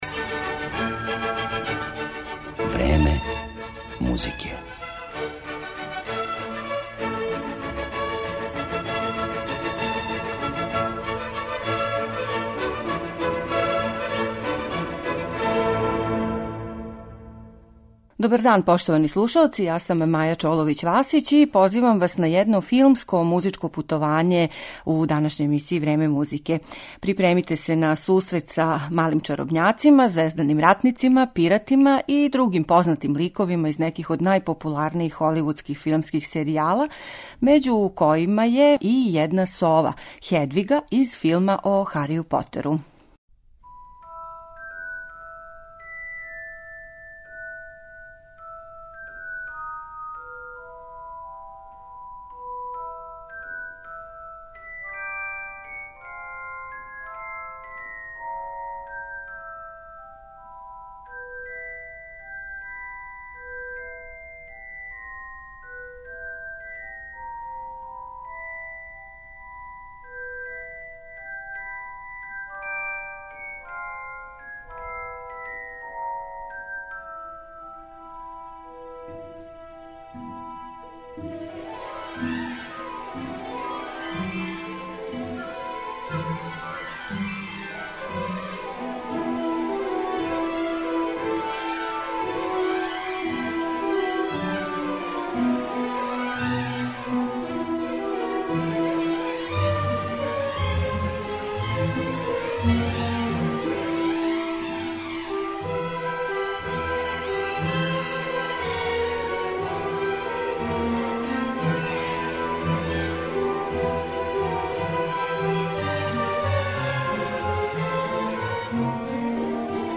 Музика из холивудских филмских серијала